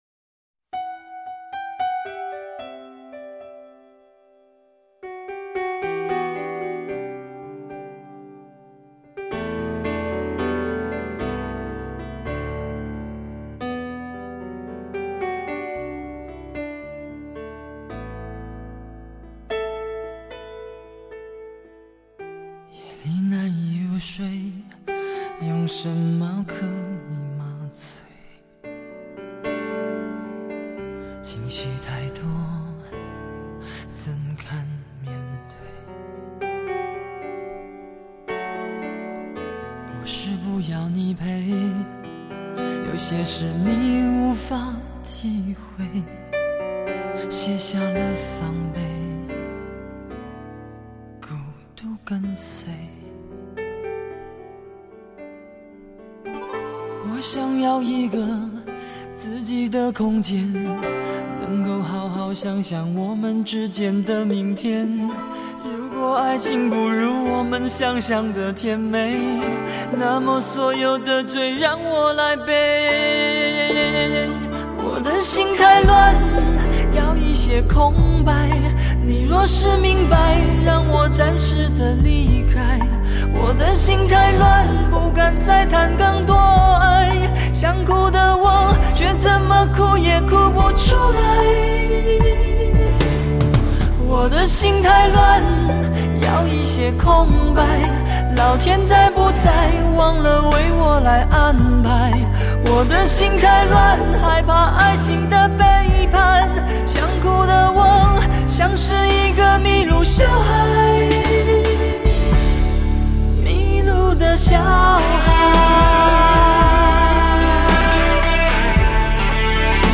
感觉歌曲表达的情境随之伤感